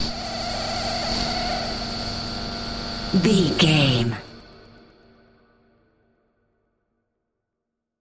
Alien Scream High
Sound Effects
Atonal
ominous
eerie
synthesiser